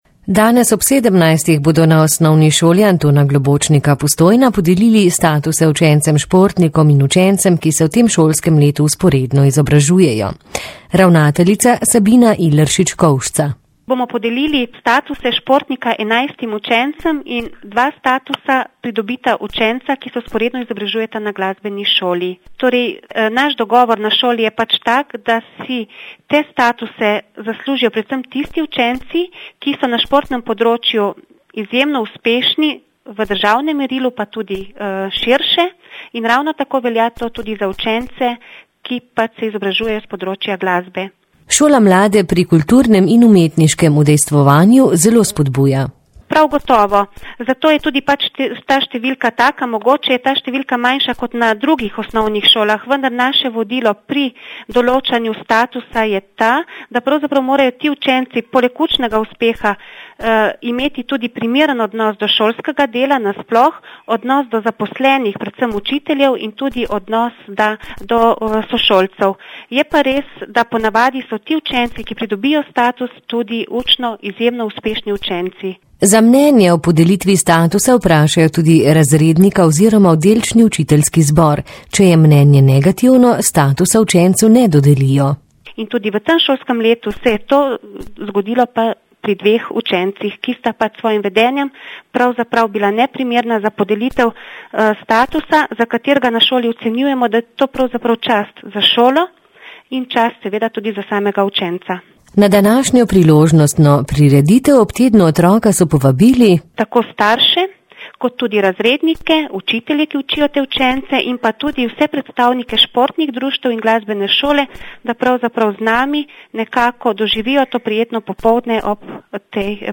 Več v pogovoru